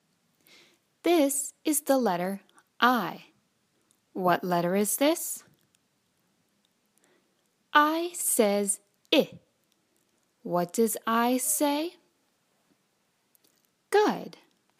Letter I (short)